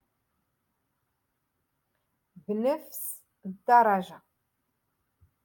Moroccan Dialect- Rotation Six - Lesson TwoEleven